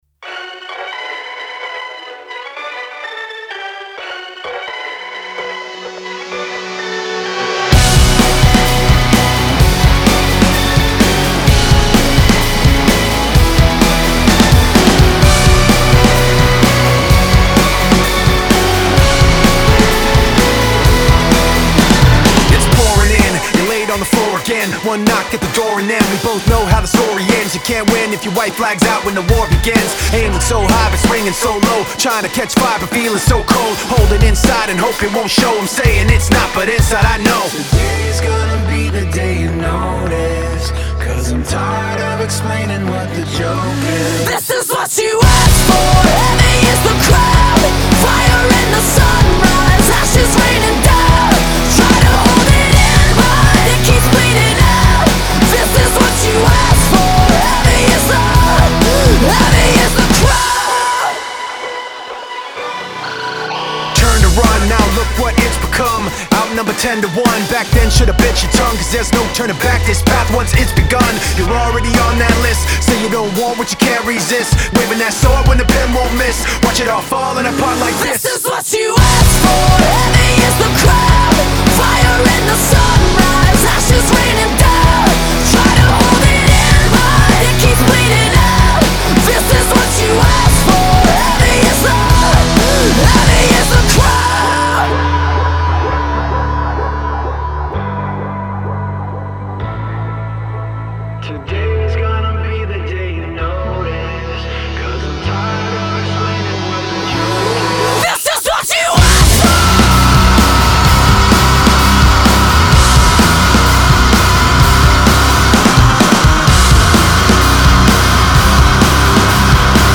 alternative metal nu metal